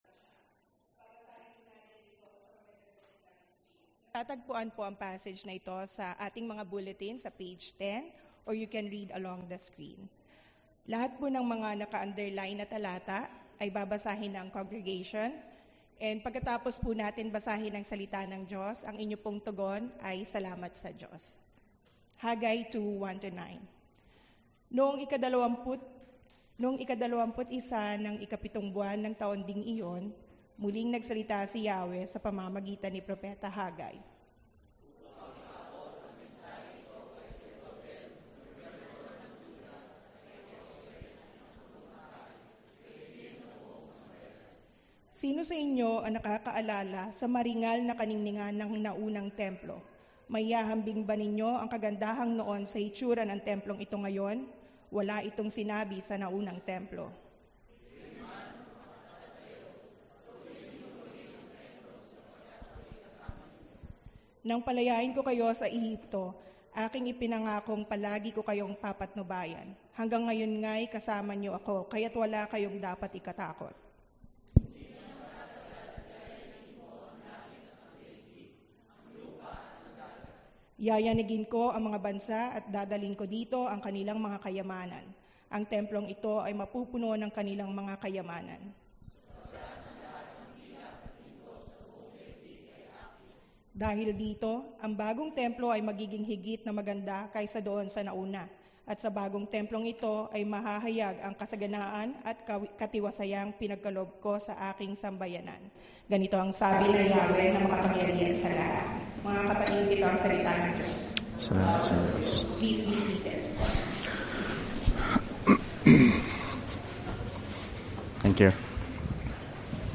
Crossroads Church Dubai - Filipino-speaking evangelical church in Dubai
APril-14-sermon.mp3